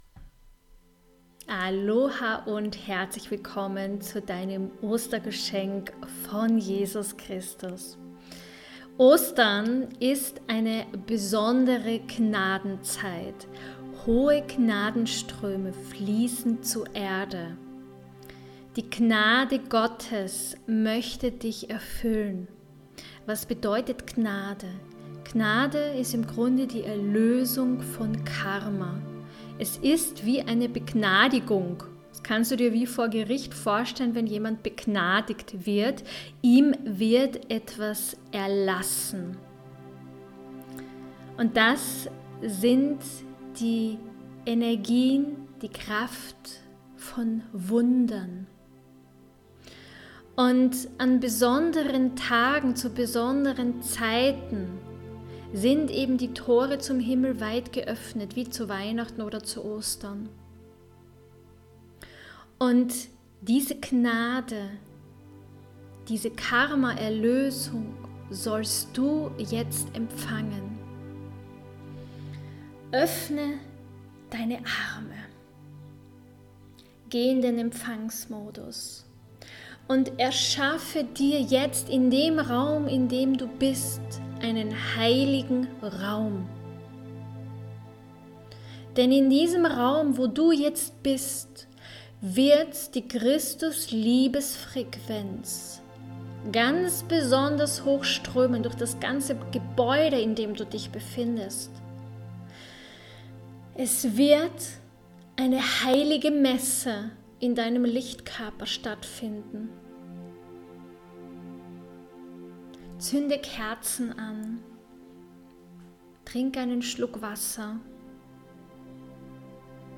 Meditation mit Jesus Christus, um das alte Kreuz des Karmas abzulegen!